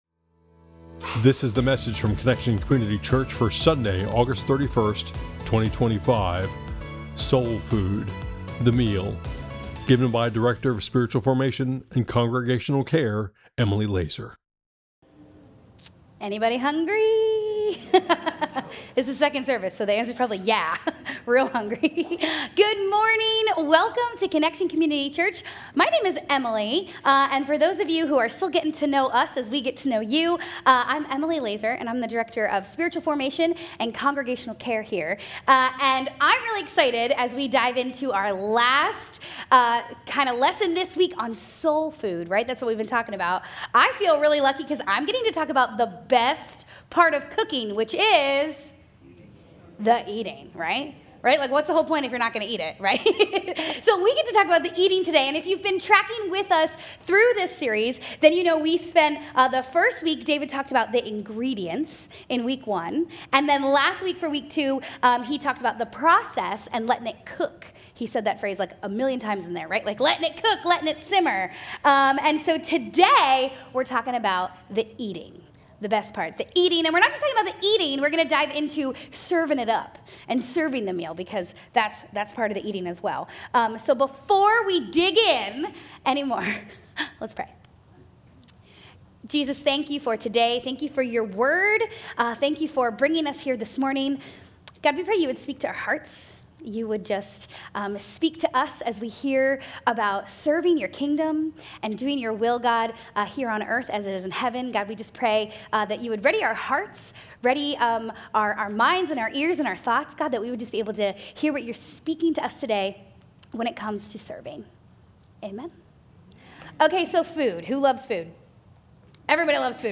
Methodist # Middletown Delaware # Dover Delaware # Connection Community Church # Christianity # Sermons